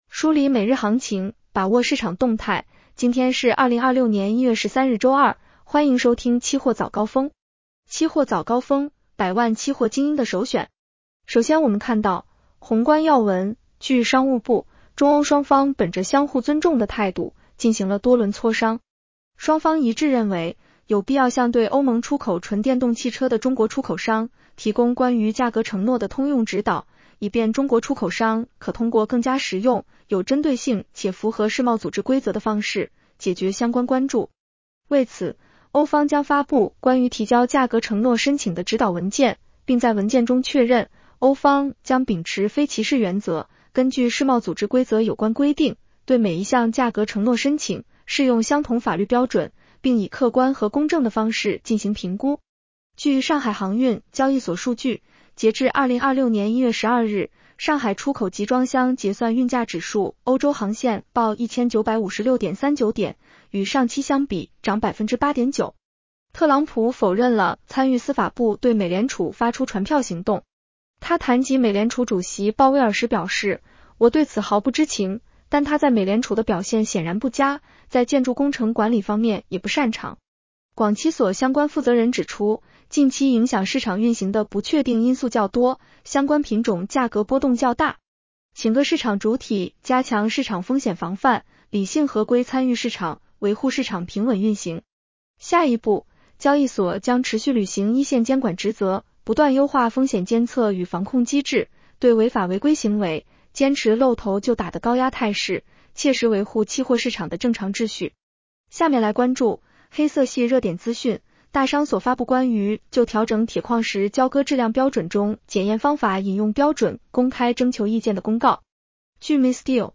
期货早高峰-音频版
期货早高峰-音频版 女声普通话版 下载mp3 热点导读 1.上金所：继续做好近期市场风险控制工作。